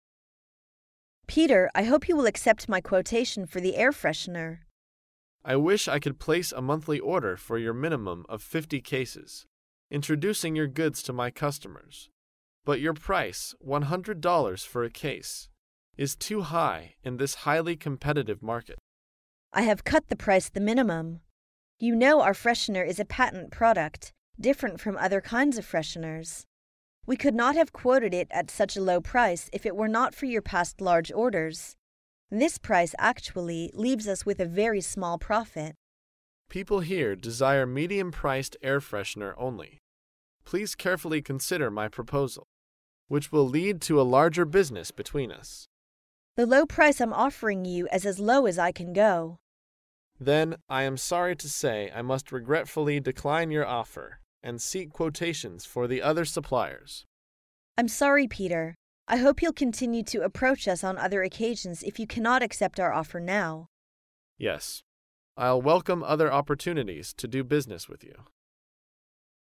高频英语口语对话 第315期:讨价还价失败 听力文件下载—在线英语听力室